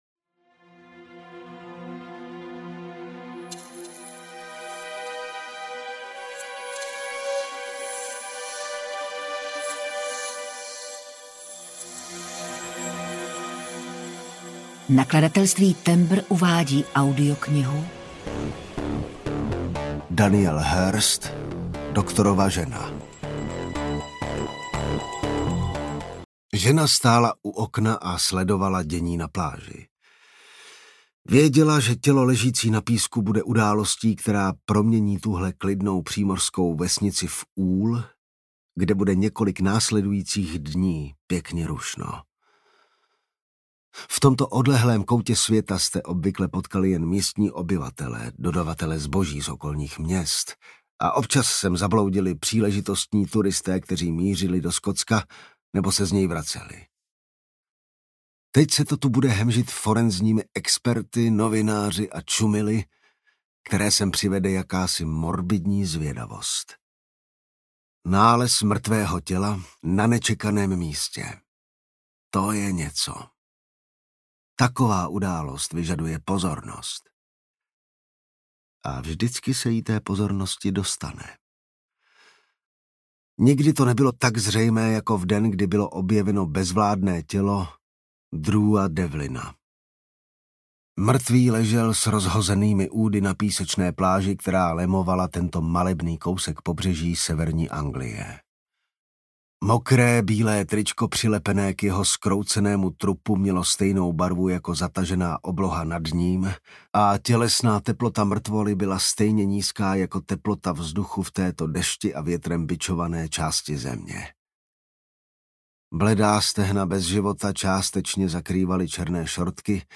Doktorova žena audiokniha
Ukázka z knihy